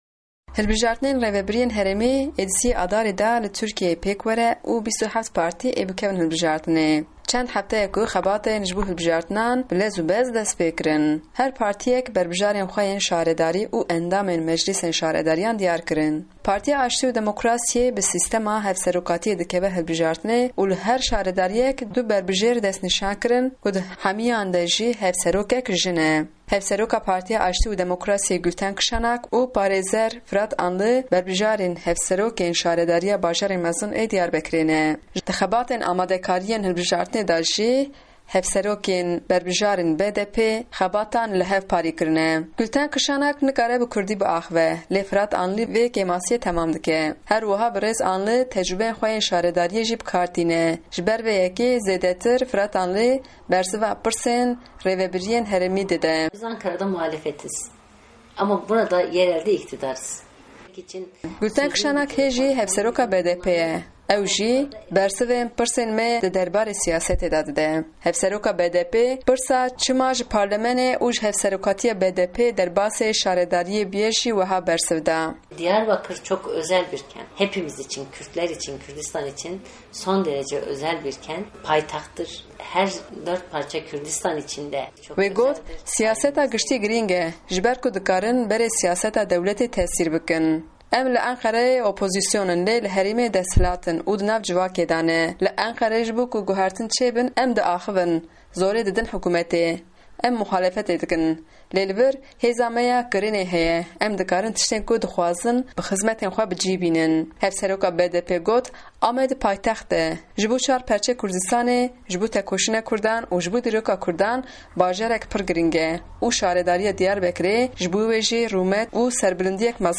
Hevpeyvin_Gultan Kişanak